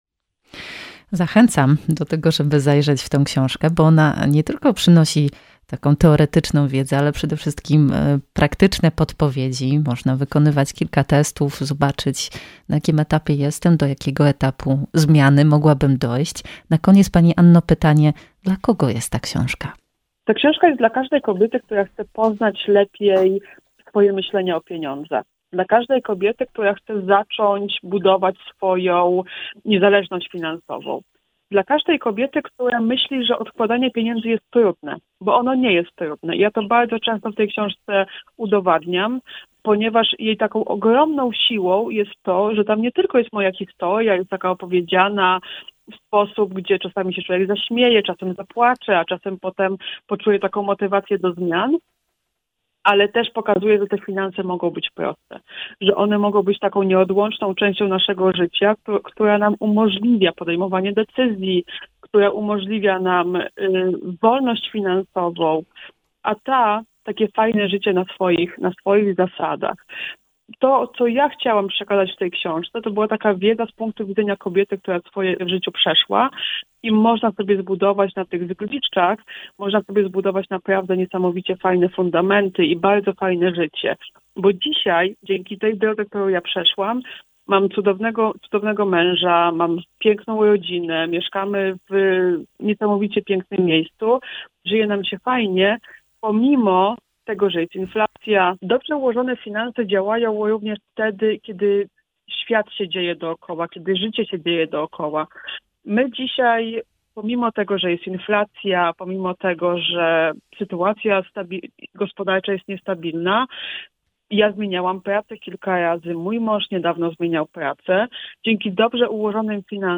Emisja wywiadu we wtorek 30 stycznia po godz. 16:10.